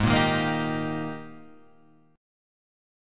Eb/Ab Chord